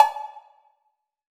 6COWBELL.wav